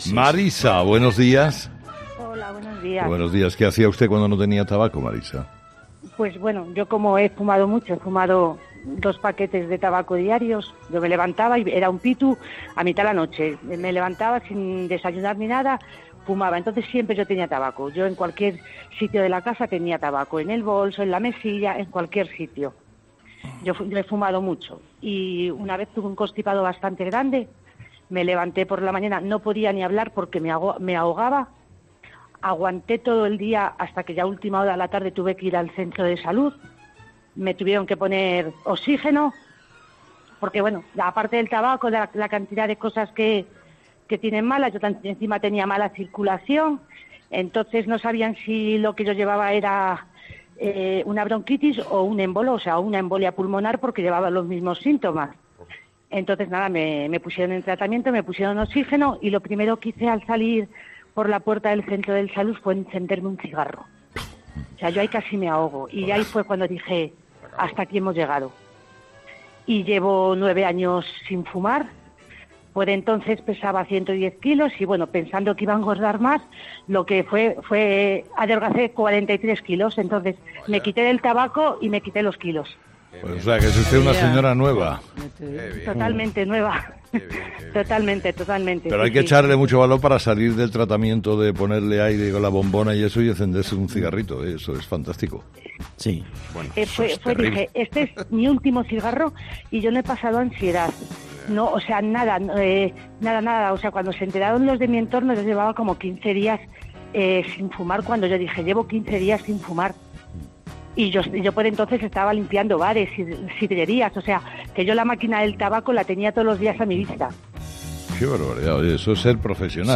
En el Día Mundial de la EPOC, los oyentes de Herrera han relatado sus experiencias con este hábito insalubre